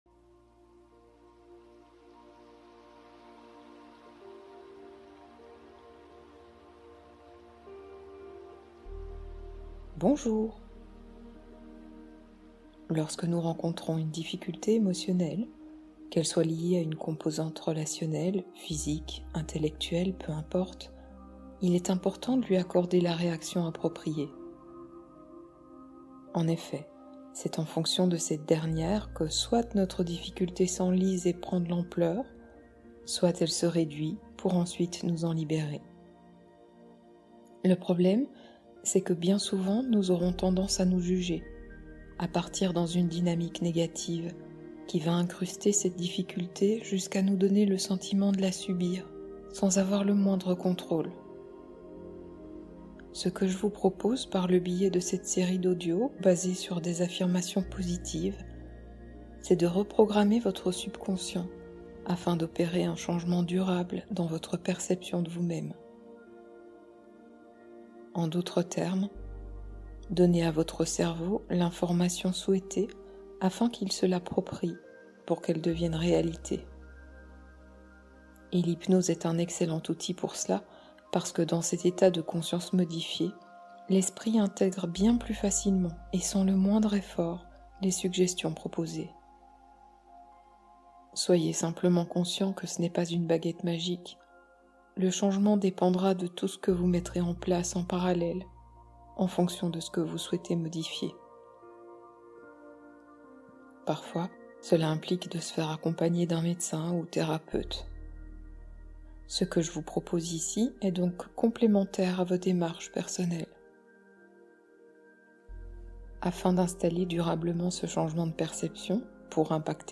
Sommeil au bout du voyage : hypnose apaisante